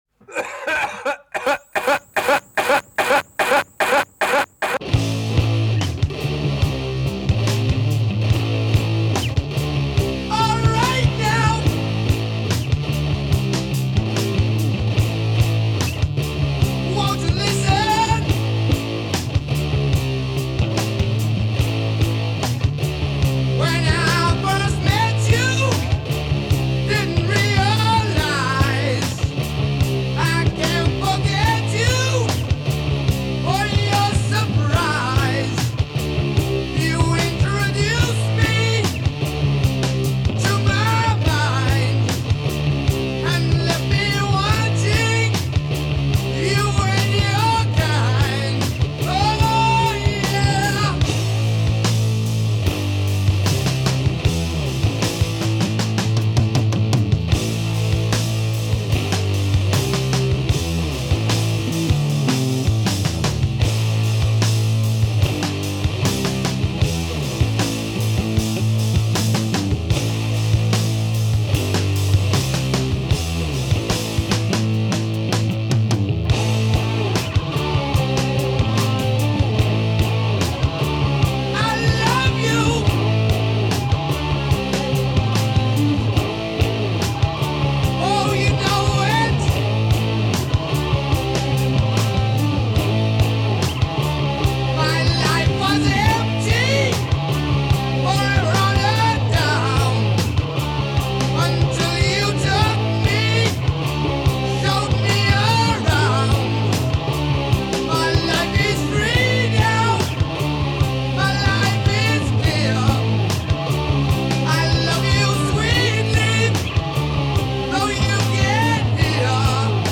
Трек размещён в разделе Зарубежная музыка / Метал.